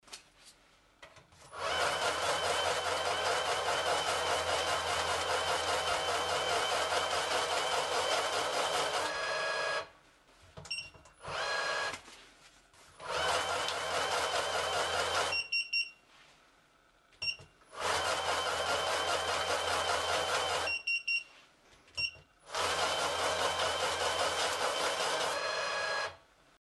Звуки счётной машинки
Портативная машинка для счета денег